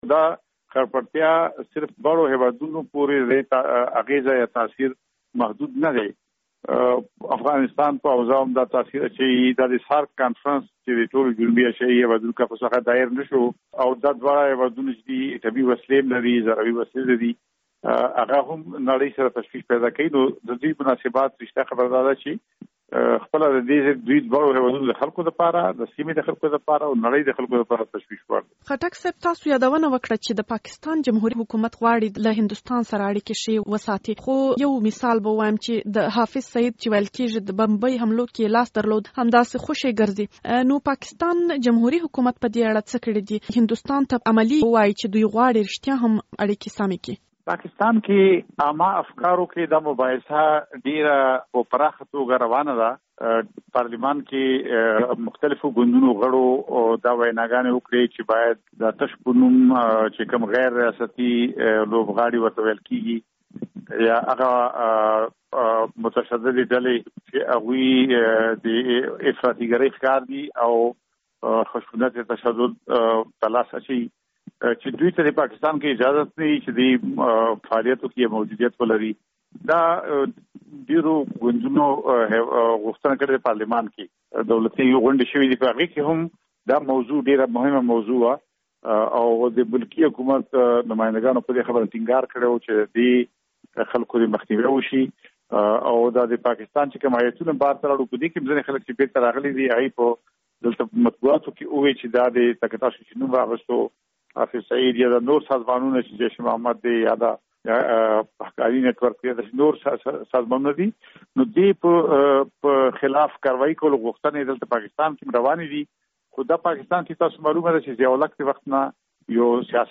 مرکه
له سناتور افراسياب خټک